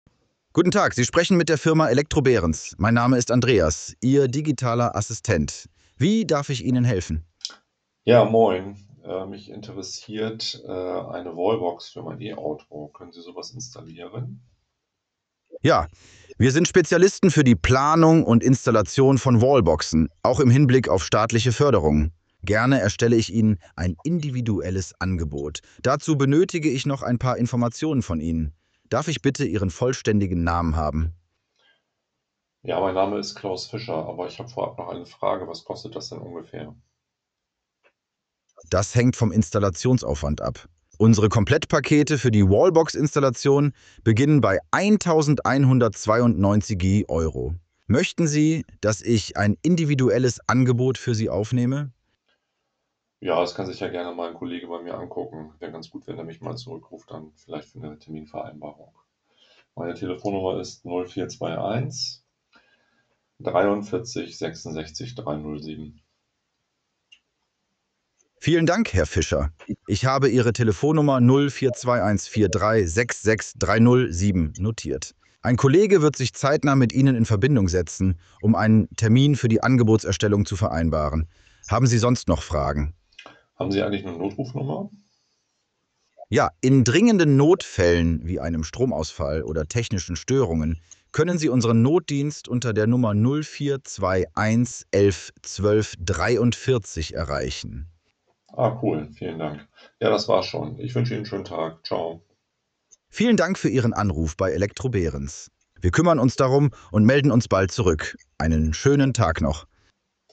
So klingt’s am Telefon.
Verbindlich, verständlich, direkt.
IntelliVoice nutzt hochwertige neuronale Sprachausgabe in natürlichem Deutsch.
IntelliVoice_Musteranruf_Elektro-Behrens.mp3